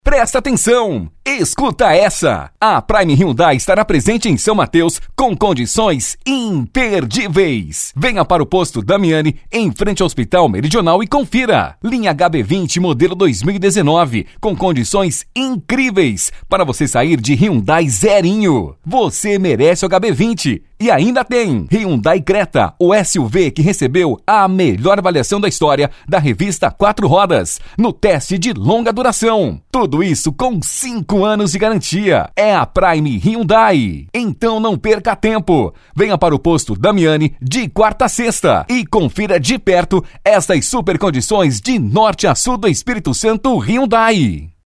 Spot Comercial
Animada